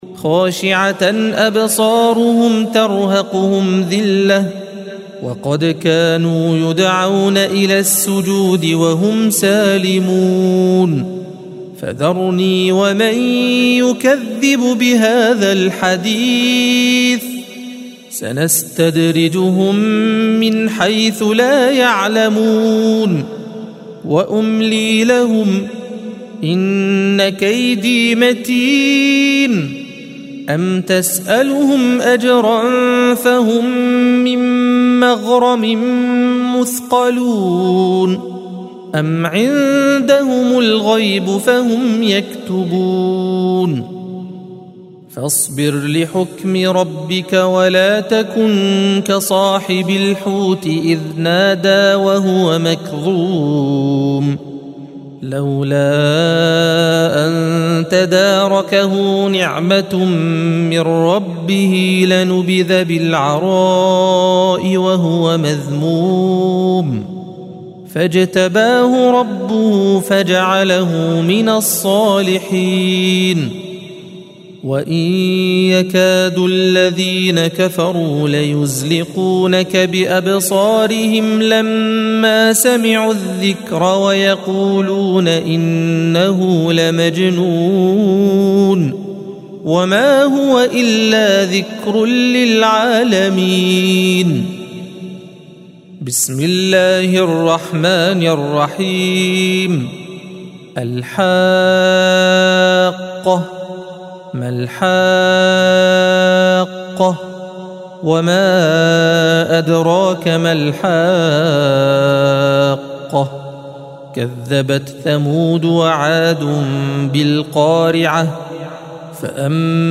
الصفحة 566 - القارئ